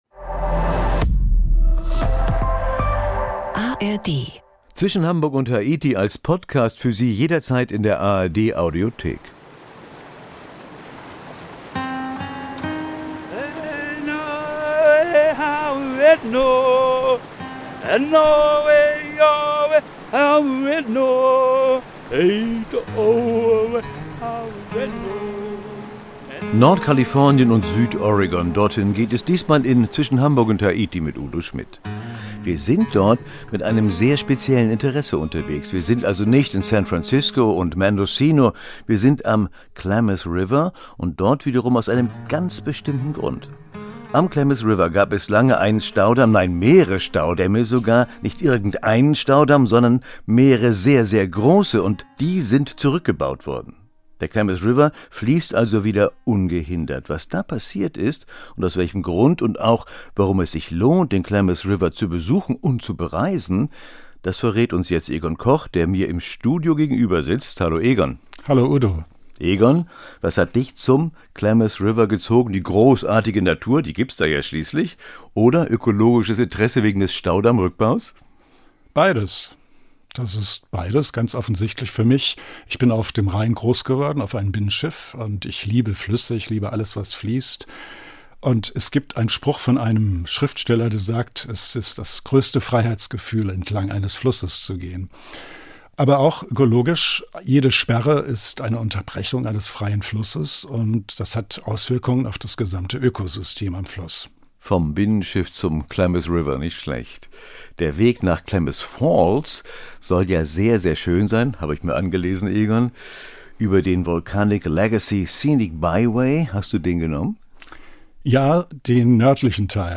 Mit dem Mikrofon rund um die Welt.